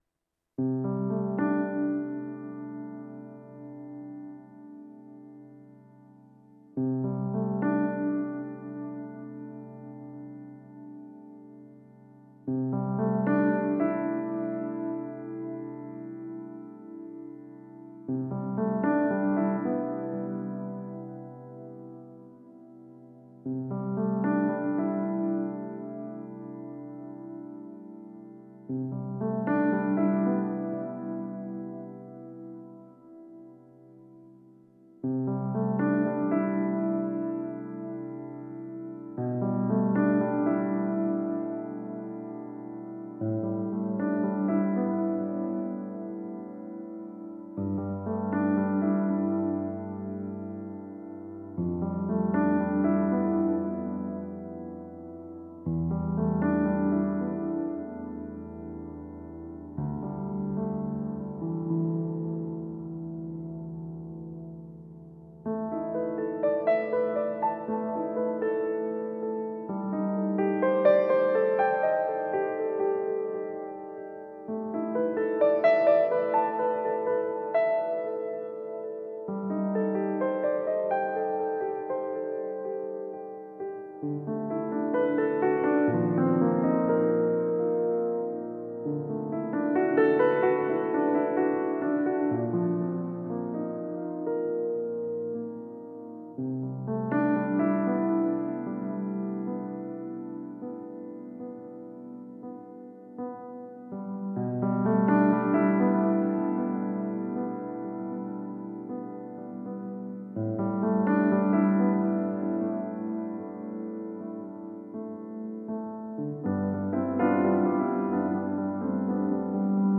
pianist/componist
Hij heeft bij verschillende kunstwerken speciale composities geschreven die op deze middag live gespeeld werden.